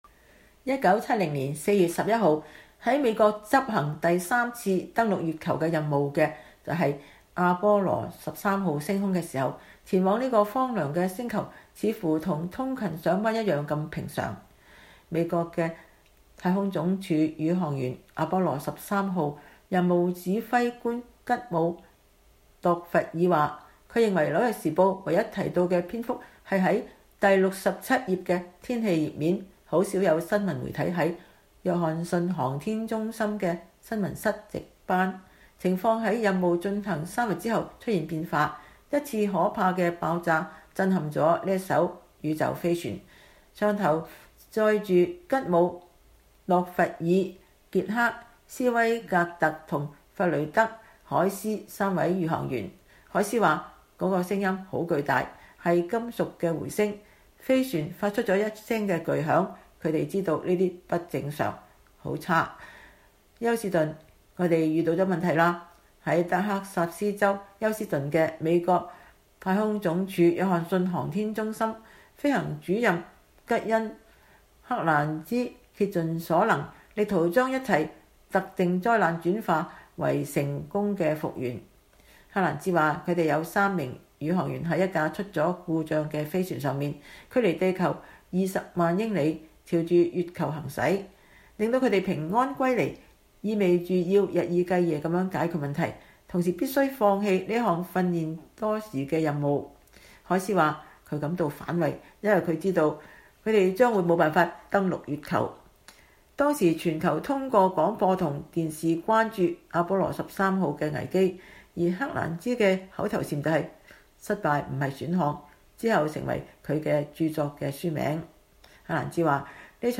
美國太空計劃最傑出的成就之一並非完成任務,而是在50年前防止了一場登月計劃出大錯的災難。 美國之音專訪了阿波羅13號宇航員和解除這項危機的負責人員。